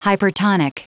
Prononciation du mot hypertonic en anglais (fichier audio)
Prononciation du mot : hypertonic